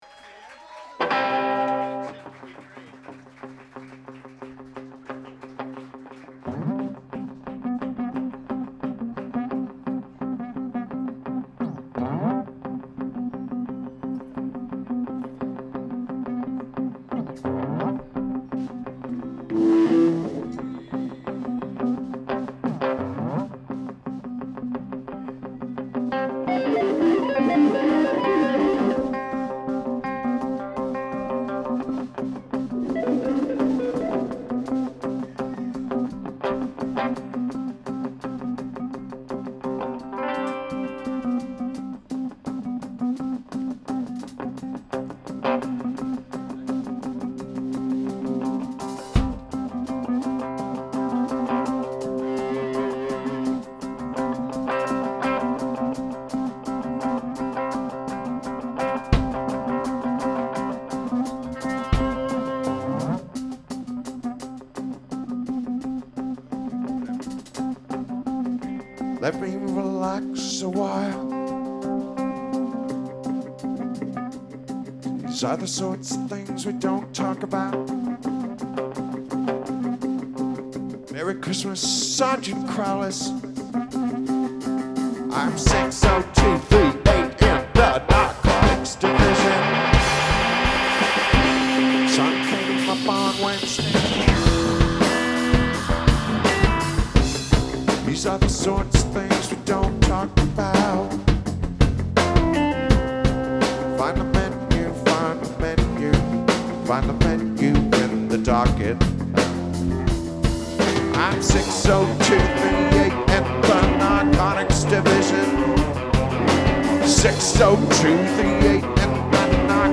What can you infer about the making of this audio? Recording: Soundboard > Sony SBM-1 > Sony D8